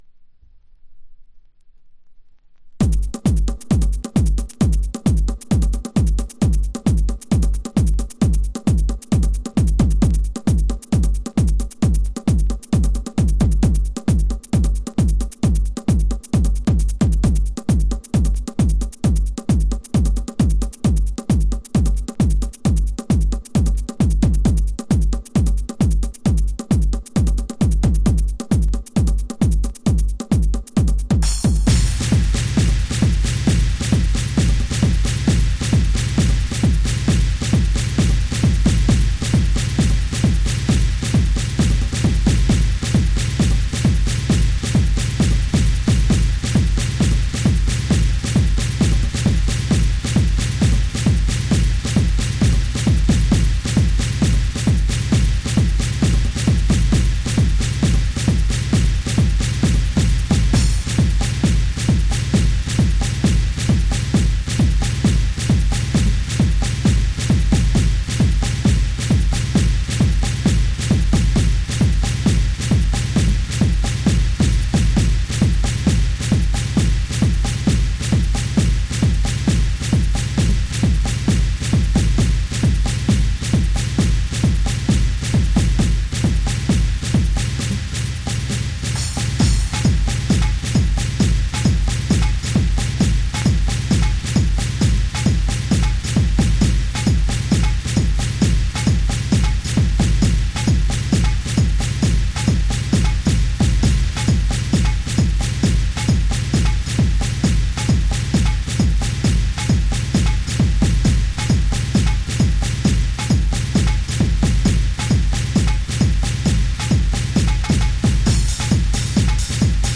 邦人アーティストによるTribalよりHard Techno。
House / Techno